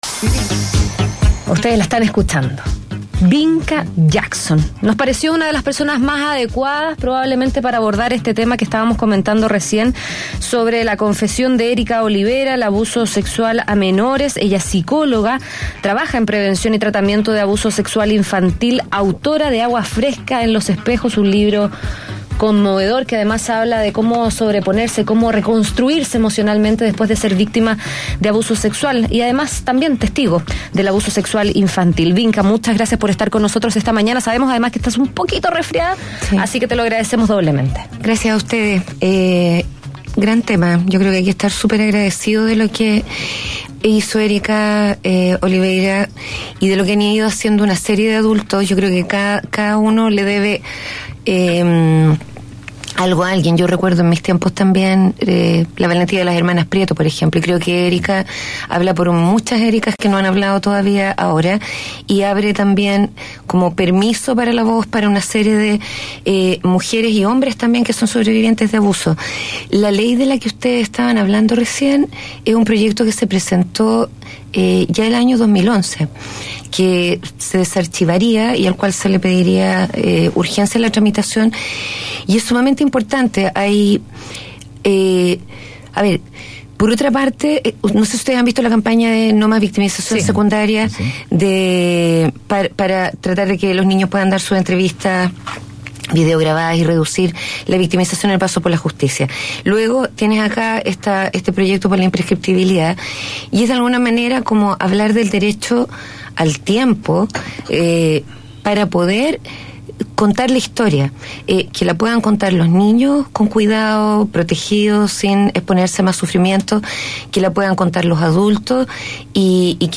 Escucha la entrevista completa realizada en Mañana Será otro Día: